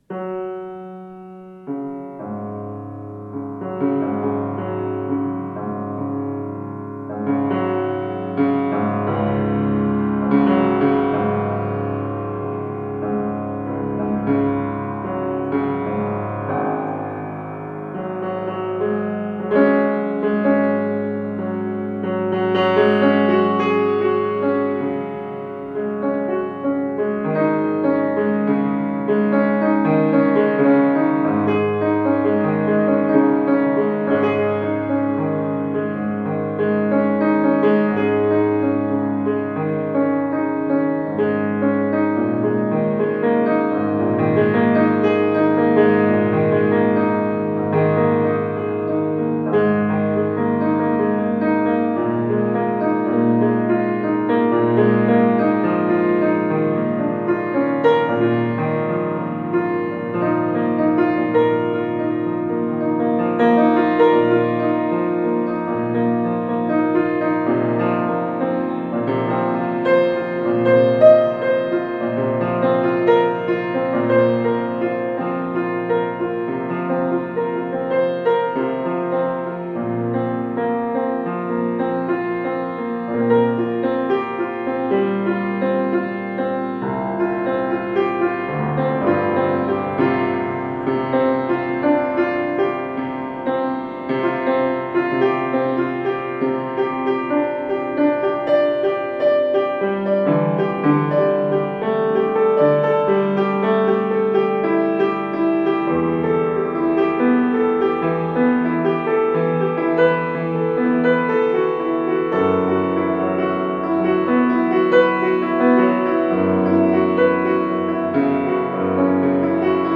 Daher kommt jetzt der erste Praxisteil: Spielt und experimentiert mit zwei, drei und vier benachbarten Quinten.
Quintus III. mit drei Nachbartönen im Quintenzirkel